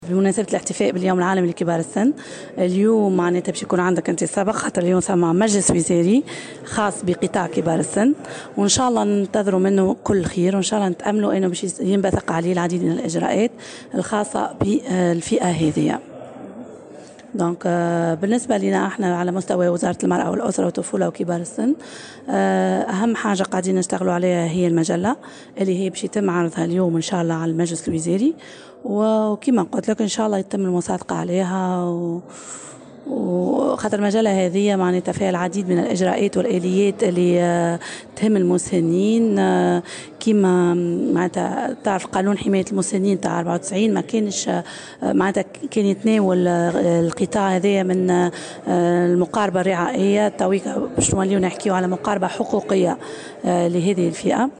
وأضافت في تصريح لـ"الجوهرة أف أم" أنه سيتم عرض مجلة تتضمن أهم الآليات الكفيلة بالعناية بهذه الفئة على المجلس الوزاري.